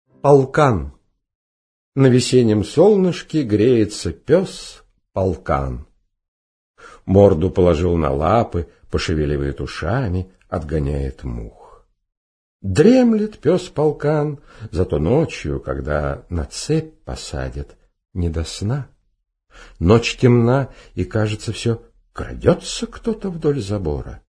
Аудиокнига Полкан | Библиотека аудиокниг